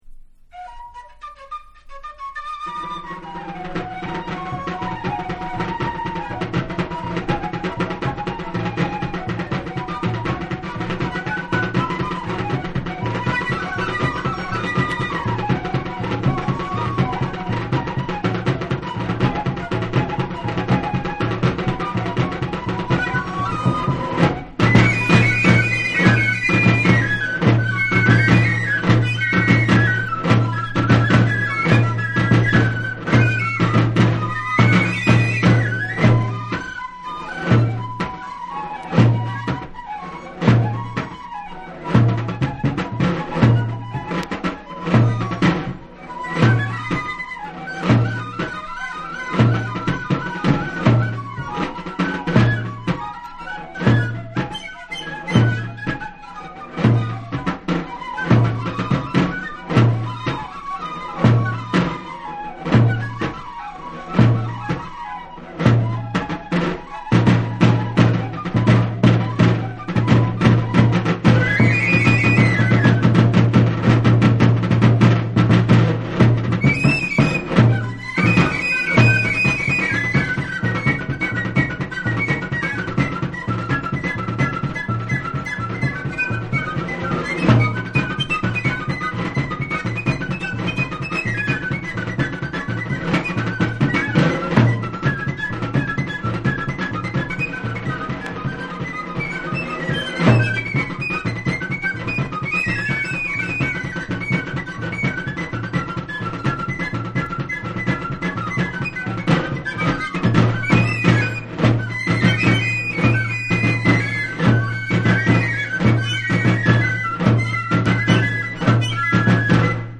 WORLD / AFRICA